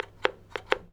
phone_hangup_dial_01.wav